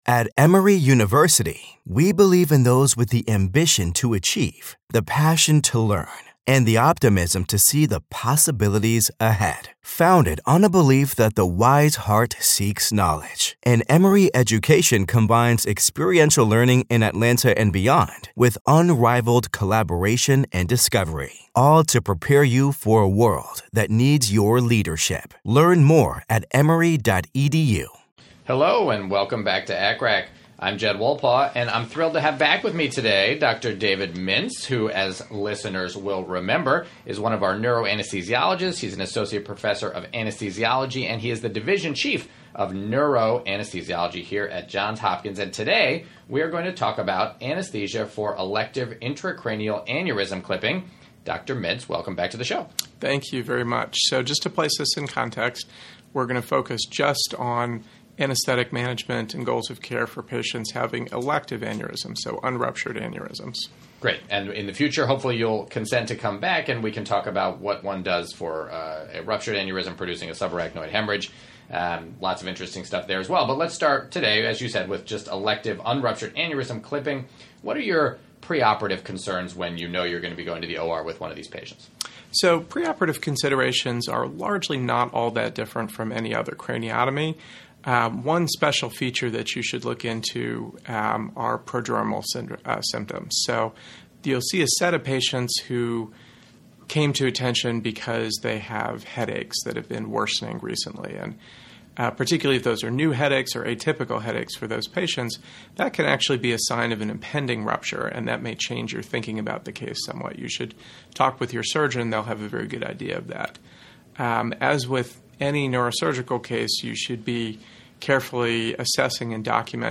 Anesthesia and Critical Care Reviews and Commentary (ACCRAC) Podcast Episode 266: Live from ASA 2023! Post-op Delirium and Patient Monitoring with Drs.